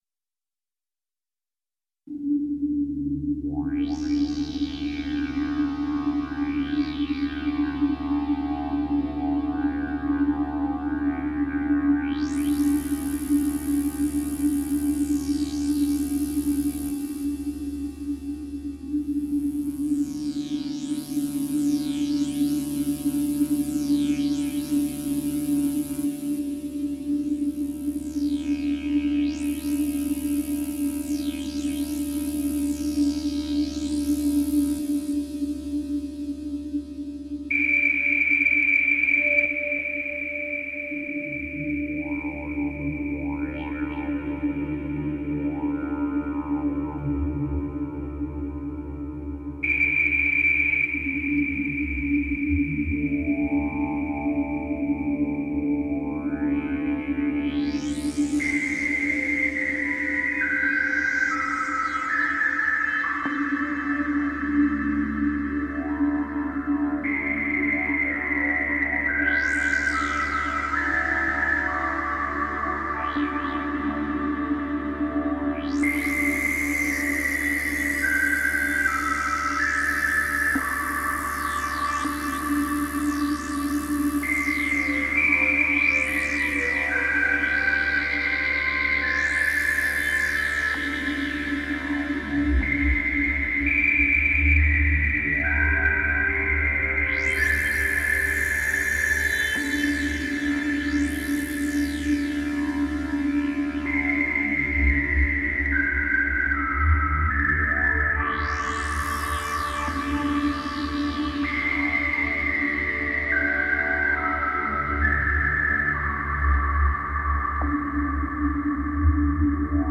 I bought a synth, the GAS bit me in the ass and I went to the shop and came out with a Roland SH-201.
Love all the sweeping you've got going on, and it sounds like you've already got a handle on it, to my ears!  8)
This sounds really good, it is kinda spooky sounding, nice one.
Fun and Trippy! man I could use a new toy.
wow -spooky at the beginning ---brilliant